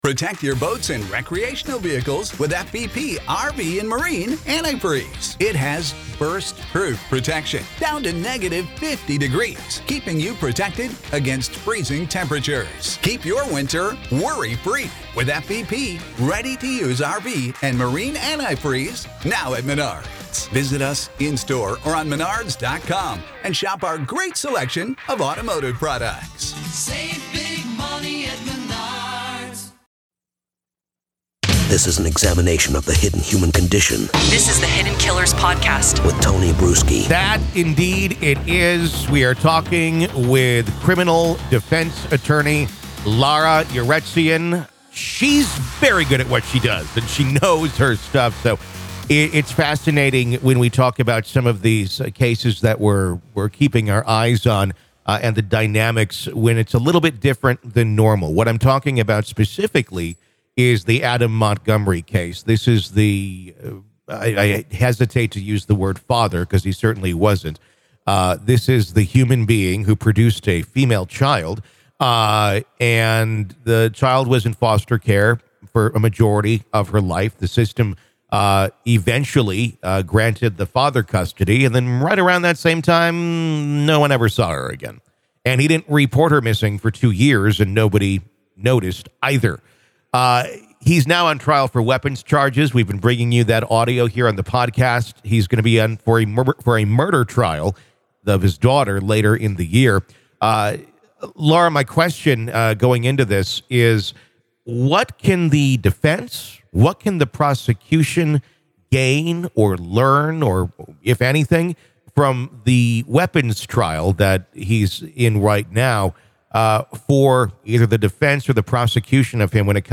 Through an intense discussion, they scrutinize the intertwining of cases, the legal implications, and the potential repercussions that past actions could have on future charges. The dialogue is a comprehensive exploration of the American legal system, procedural intricacies, and the principles of fairness and justice.